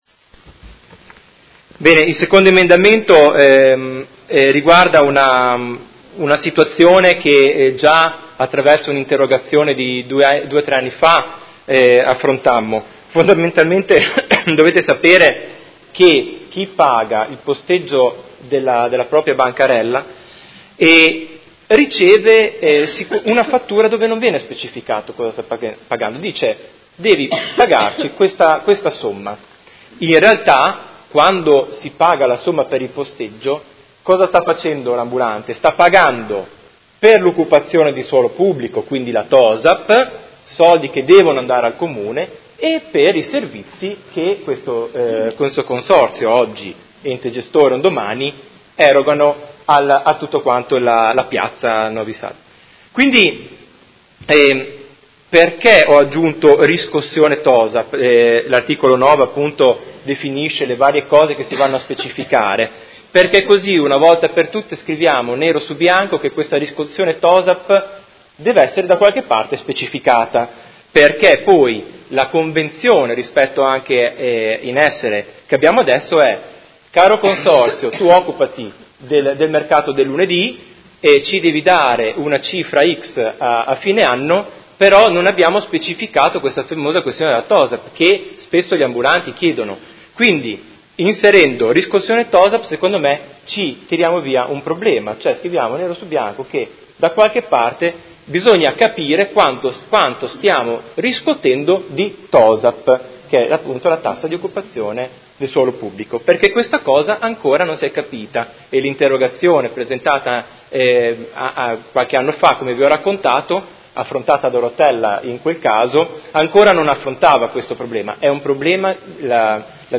Marco Chincarini — Sito Audio Consiglio Comunale